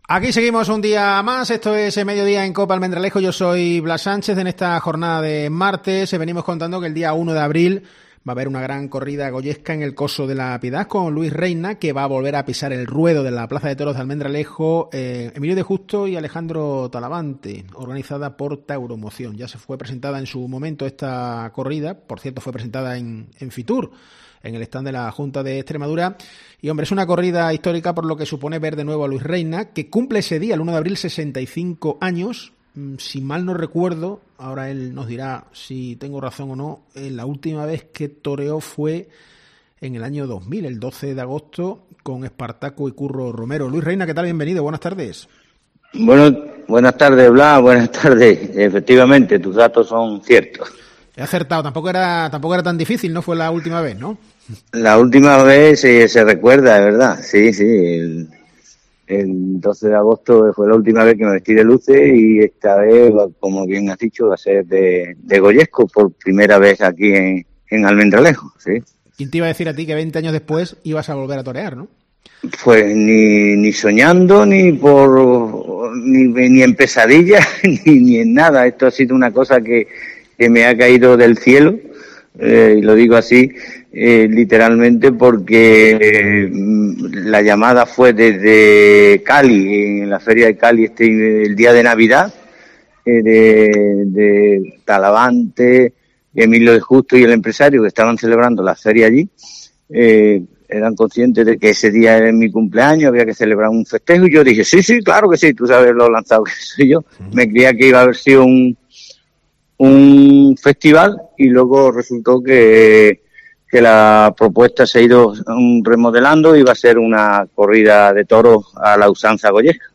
En COPE hemos hablado con él.